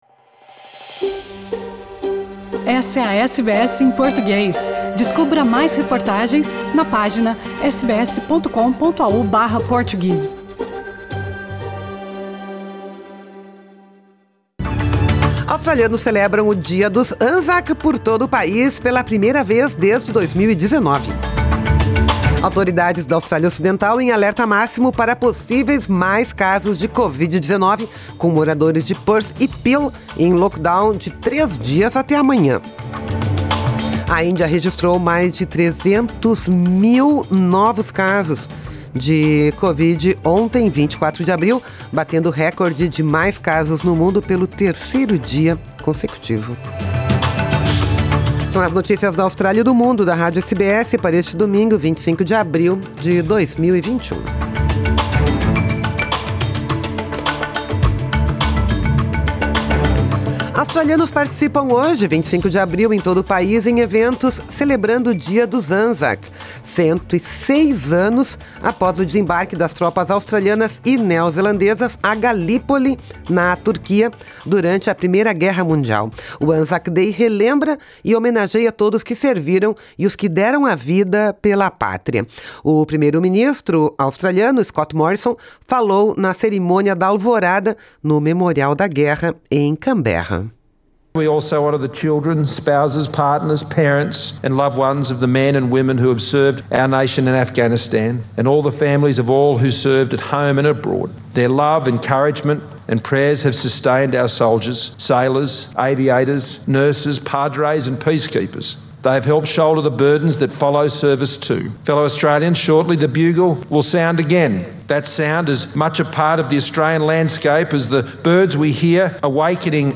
São as notícias da Austrália e do Mundo da Rádio SBS para este domingo, 25 de abril de 2021